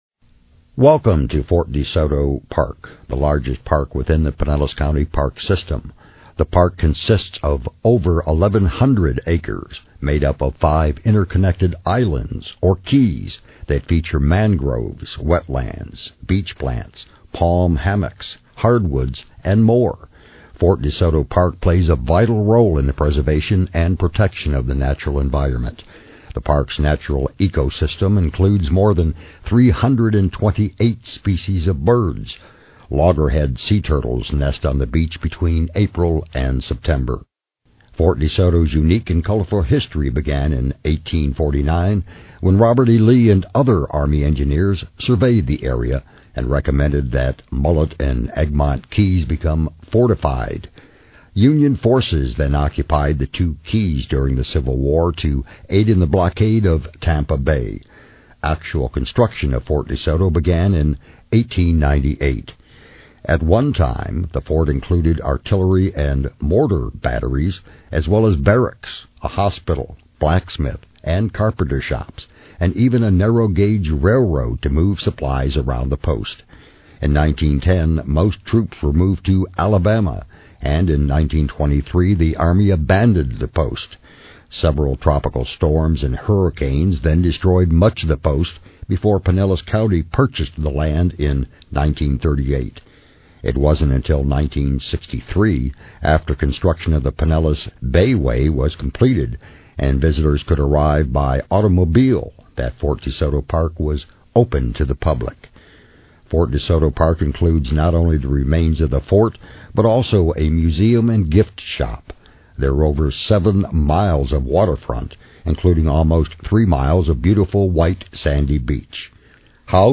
Audio Tour Beach & Boat Ramp Parking Reserve Campsite or Shelter Park Finder